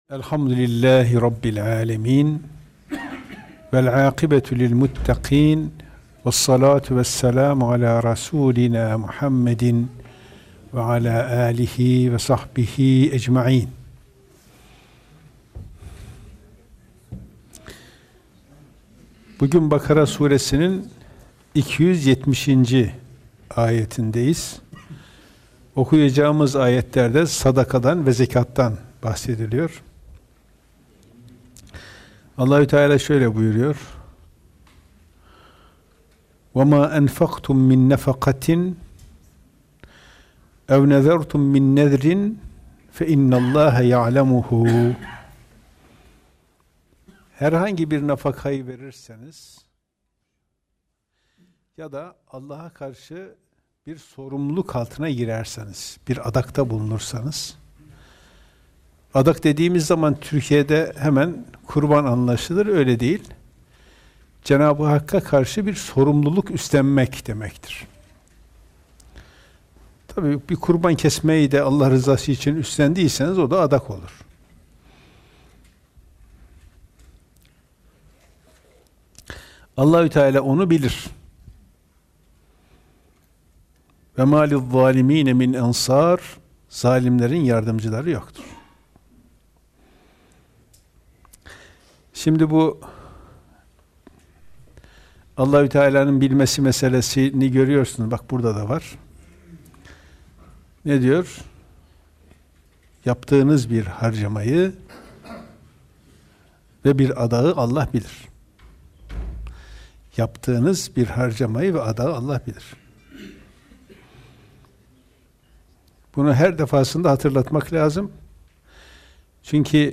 Gösterim: 3.505 görüntülenme Kur'an Sohbetleri Etiketleri: bakara suresi 270-273. ayetler > kuran sohbetleri > yardımı incitmeden yapmak Bismillahirrahmanirrahim.